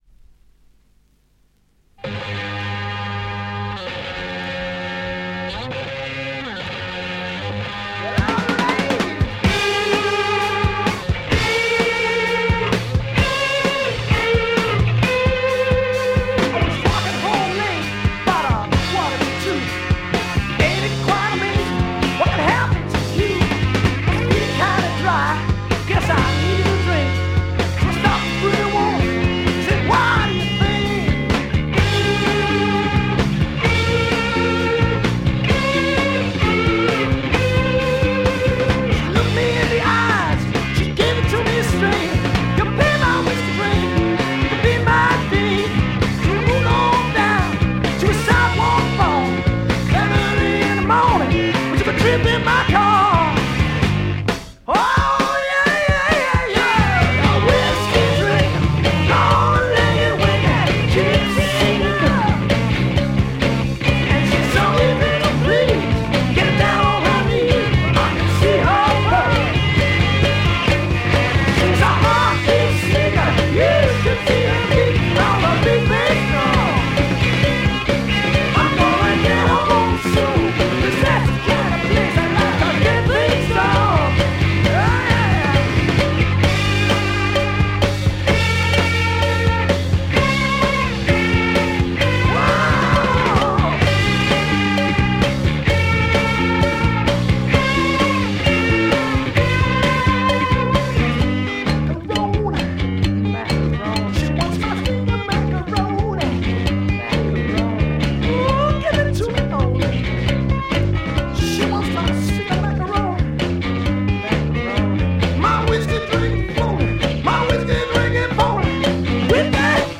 UK Prog rock